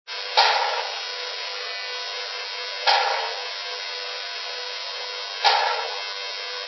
Mine is still working, but every so often the fan bearings sound like a bicycle with playing cards in the spokes.
I could not get a good recording, there is a lot of background noise. The recording makes it sound like a pop, but it is actually more like an electric pencil sharpener for a second or two.